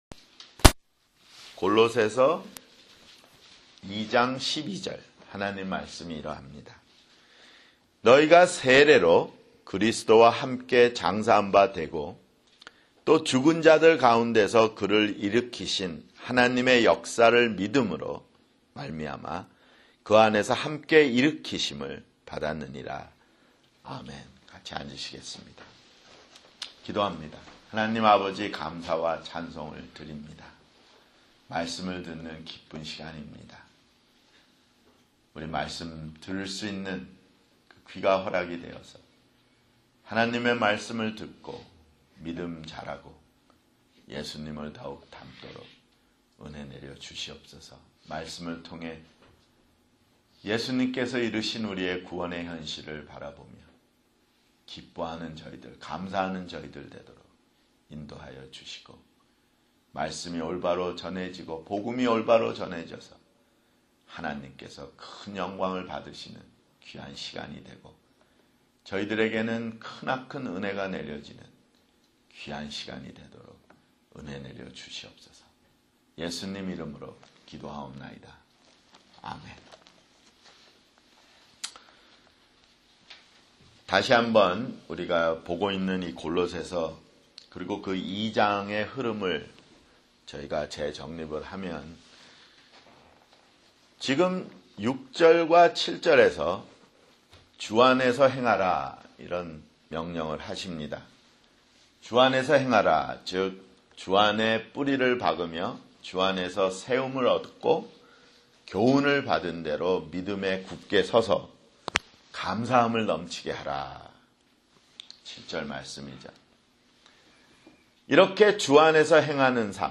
[주일설교] 골로새서 (46)